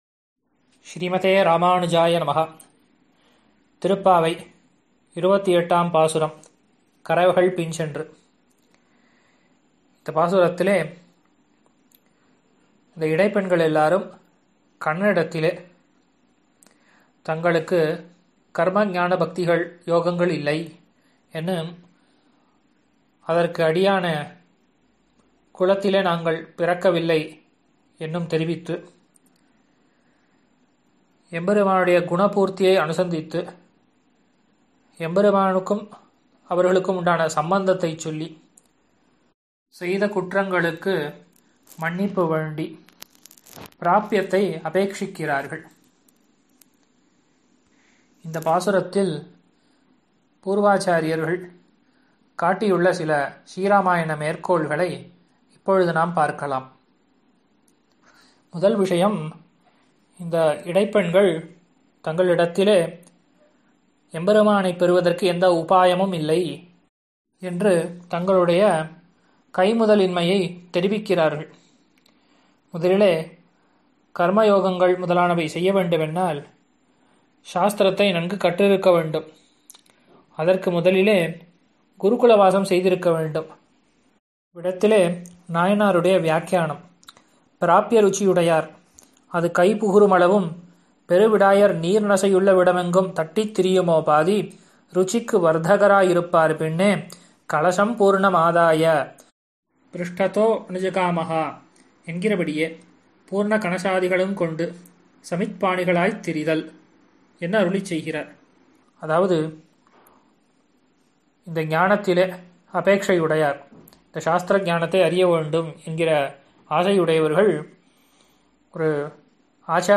மார்கழி ௴ உபன்யாசம்